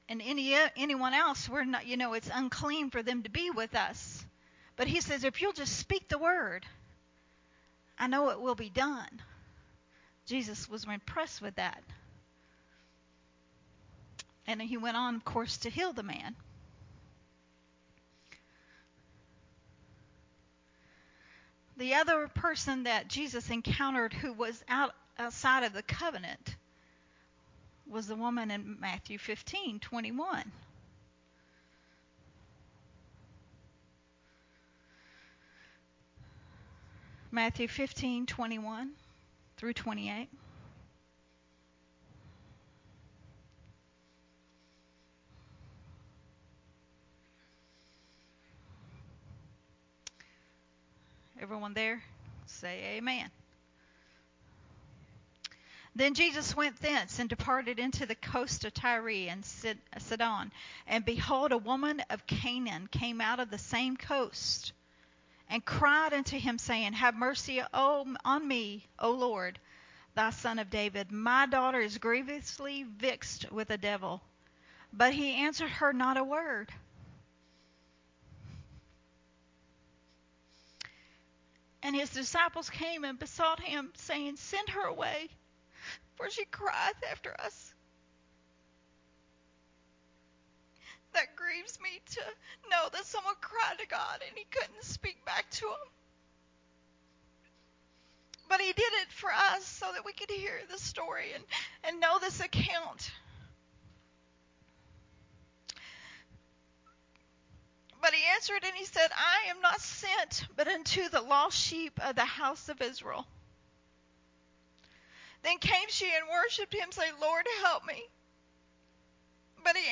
teaching
recorded at Unity Worship Center